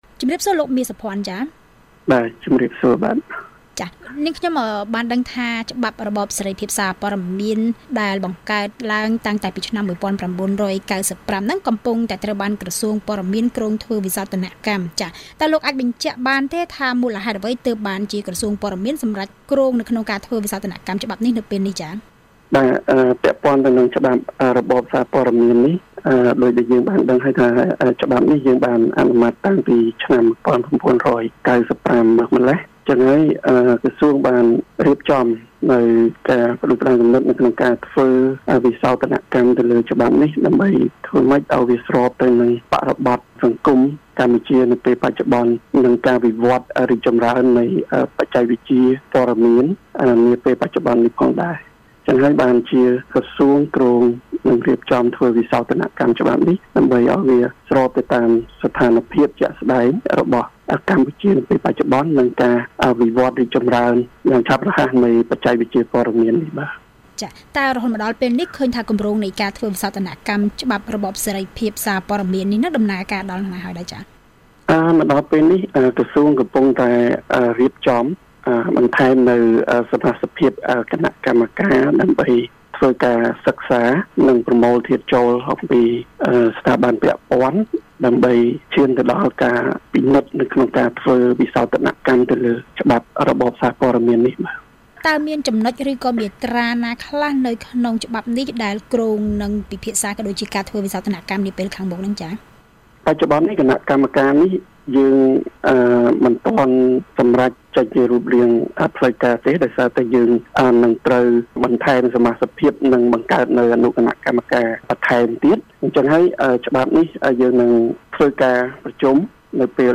បទសម្ភាសន៍VOA៖ ក្រសួងព័ត៌មាននឹងធ្វើវិសោធនកម្មច្បាប់សារព័ត៌មាន